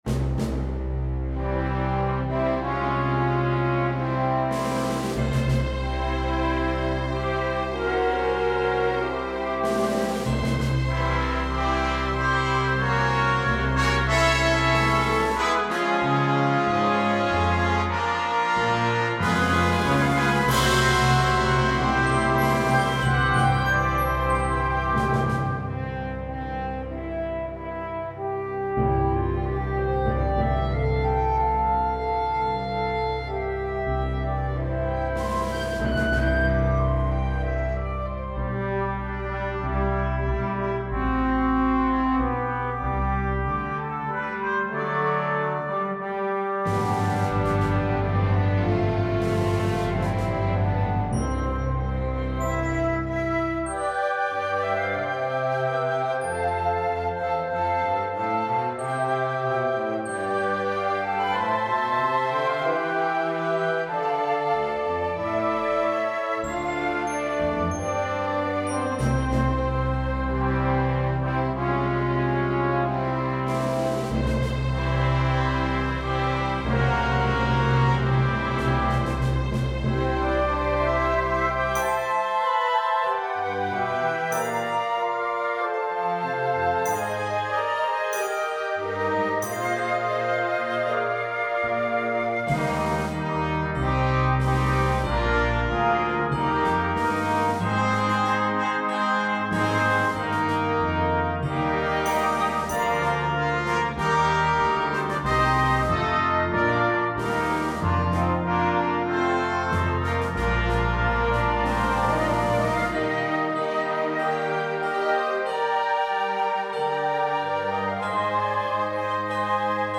It develops and builds to a ceremonial last verse.